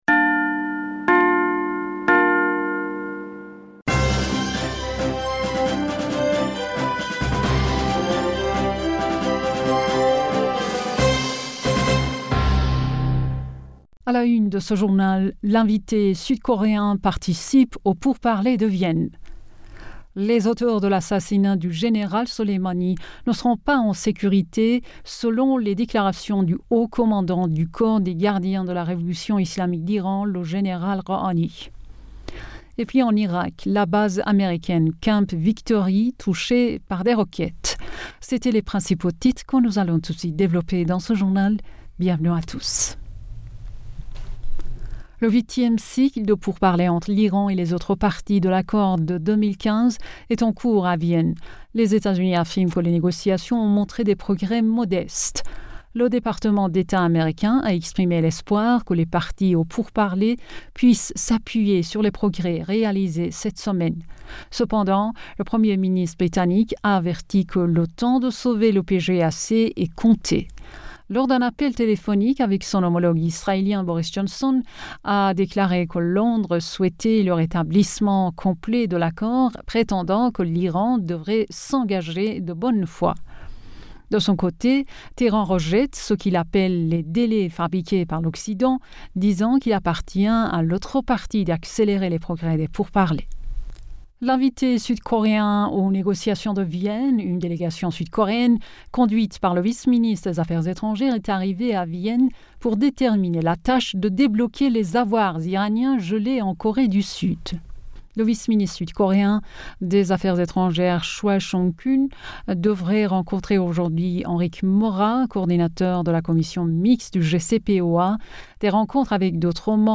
Bulletin d'information Du 05 Janvier 2022